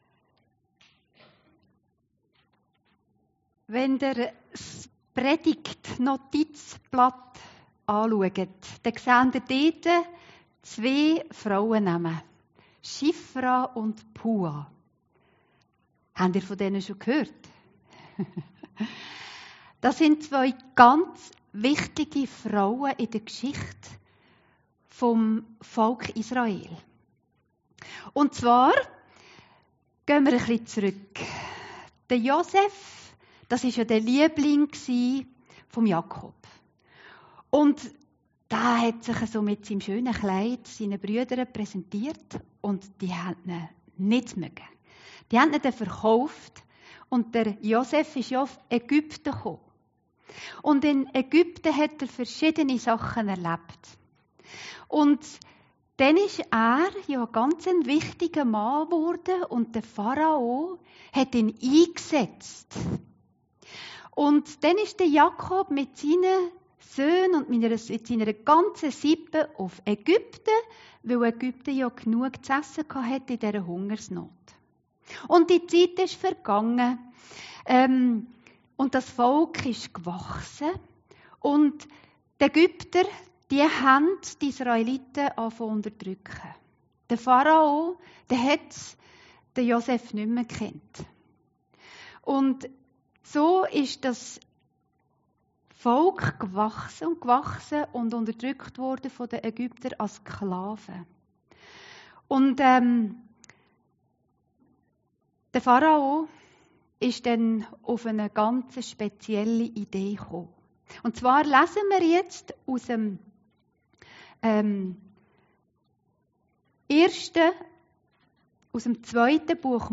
Predigten Heilsarmee Aargau Süd – schifra-und-pua-2-mutige-frauen